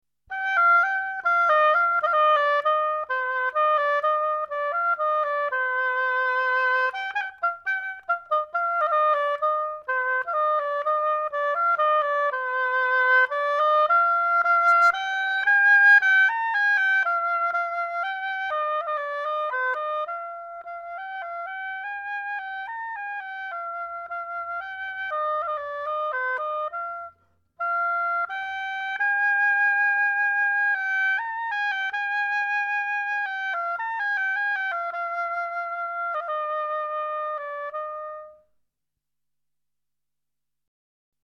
Oboe2 - Pista 02.mp3